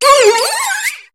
Cri de Floramantis dans Pokémon HOME.